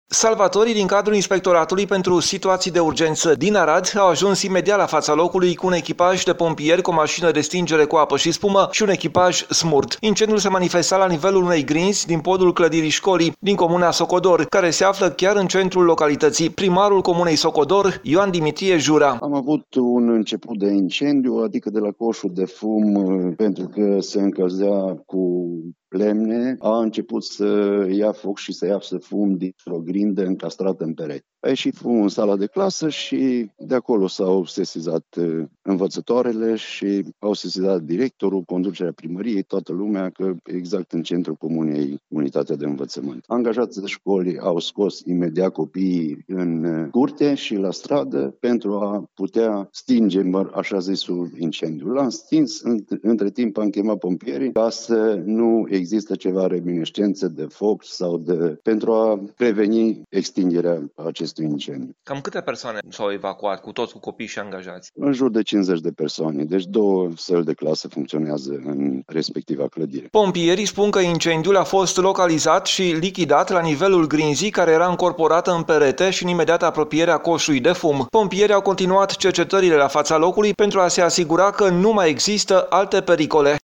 Primarul comunei Socodor, Ioan Dimitrie Jura, spune că în respectiva școală funcționează două săli de clasă.